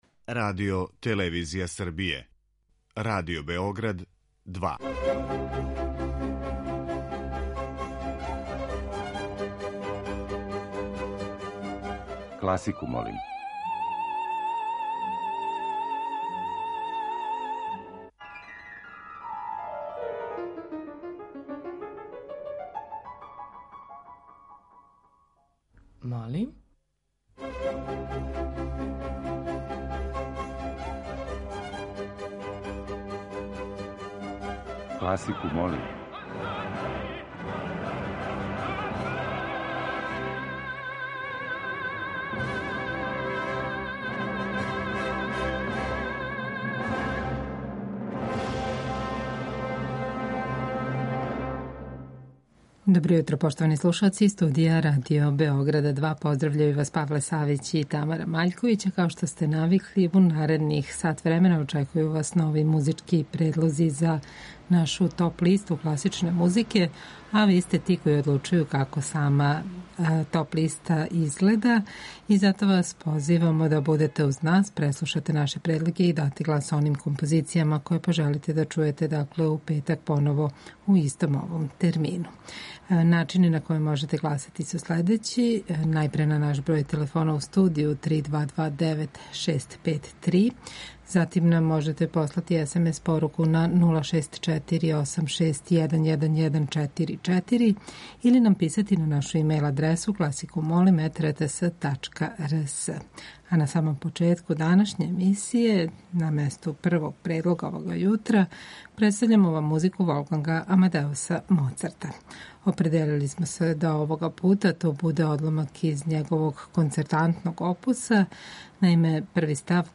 У недељи када прослављамо Божић по јулијанском календару, слушаћете одабране нумере православне духовне музике.
Уживо вођена емисија Класику, молим окренута је широком кругу љубитеља музике. Разноврсност садржаја огледа се у подједнакој заступљености свих музичких стилова, епоха и жанрова.